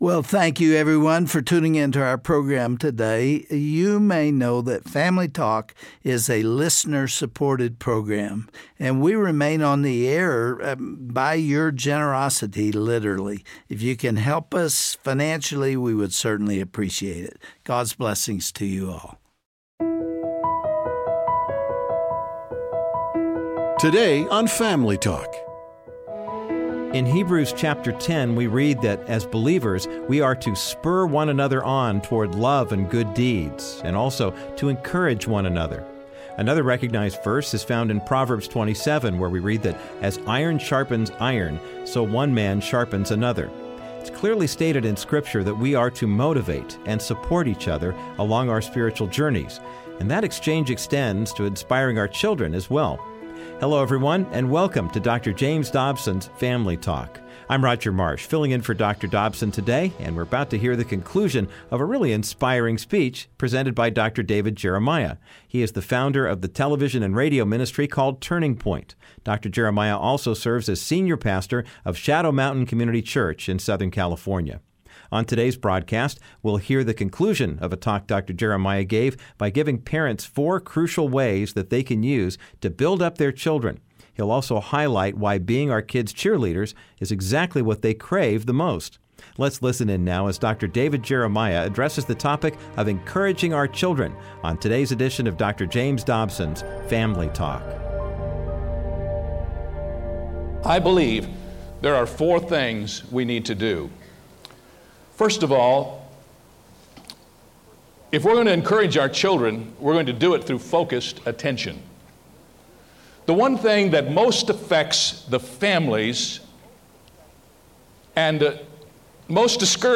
On this Thursday broadcast of Family Talk, Dr. David Jeremiah founder and President of Turning Point radio and television ministries, will finish speaking on the topic of encouraging our children.he will provide four practical ways parents can empower and support their kids and why children crave that security.